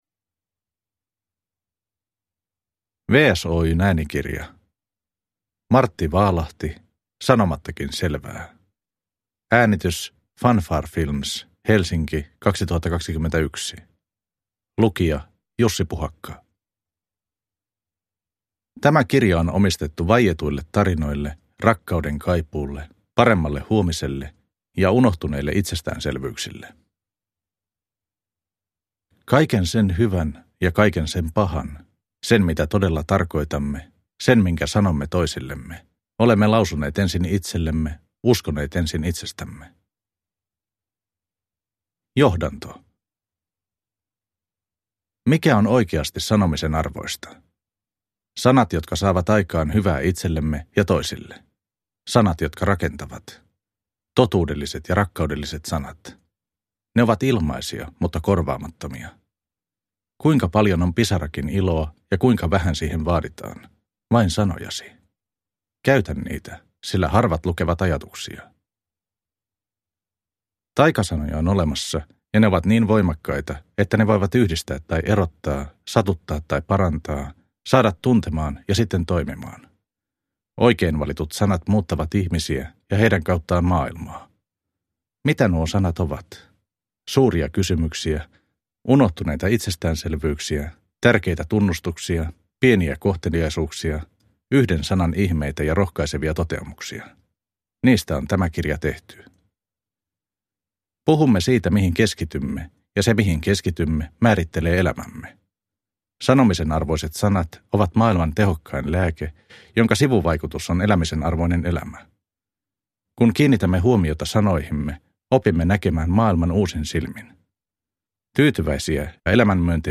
Sanomattakin selvää? – Ljudbok – Laddas ner